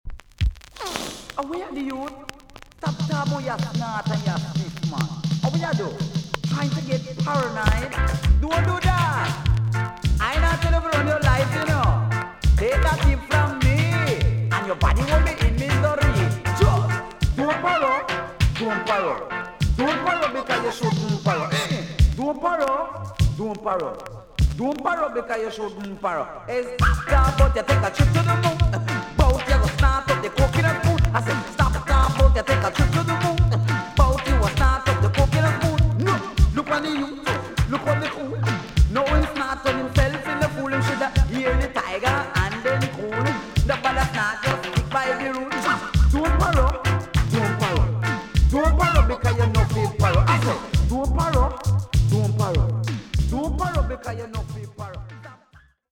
TOP >80'S 90'S DANCEHALL
EX-~VG+ 少し軽いチリノイズがありますが良好です。
1987 , NICE DJ STYLE!!